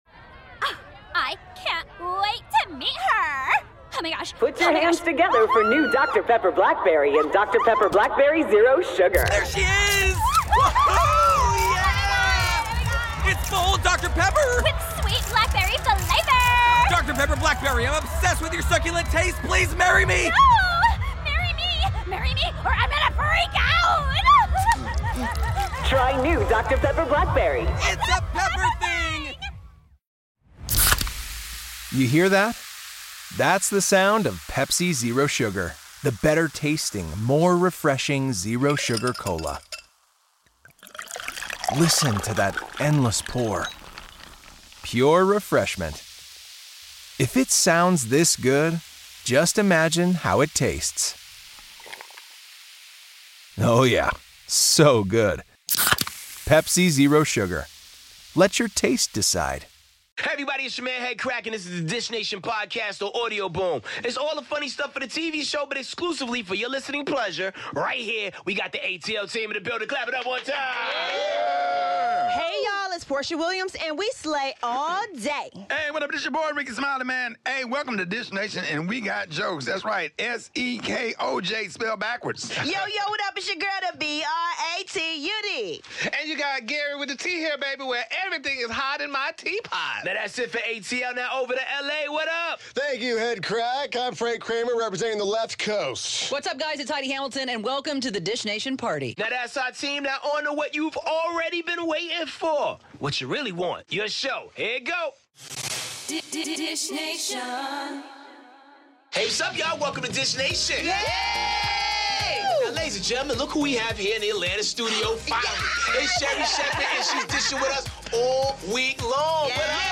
Rihanna and her billionaire boyfriend call it quits, Serena Williams has Meghan Markle's back and Taylor Swift gives Justin Bieber the boot! Sherri Shepherd is in studio with us so watch today's Dish Nation!